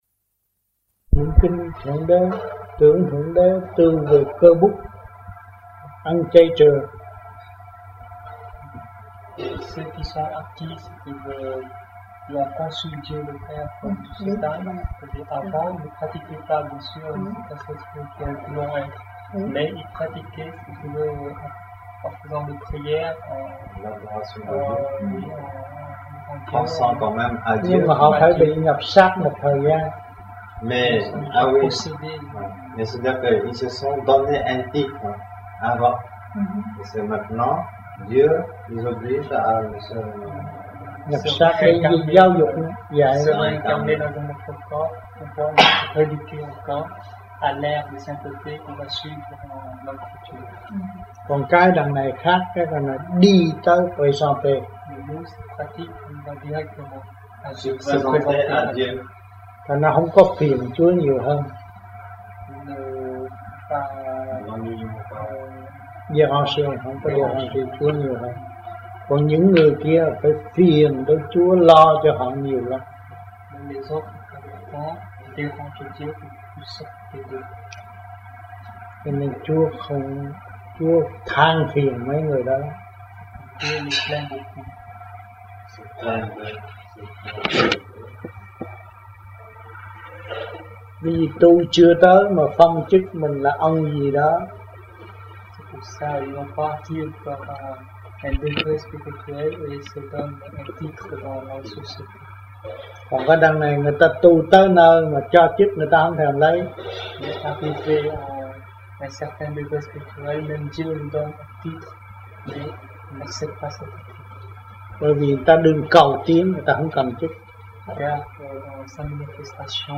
1980-12-15 - GIEN - THẦY GIẢNG TẠI NHÀ THƯƠNG GIEN